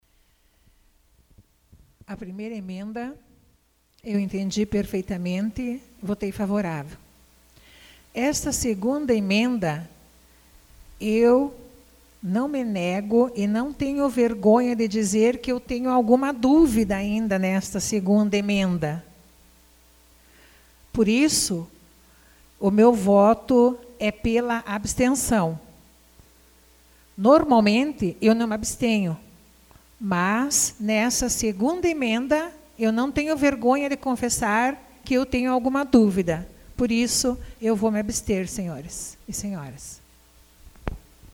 Discussão emenda 02 AVULSO 03/06/2014 Lindamir Ivanoski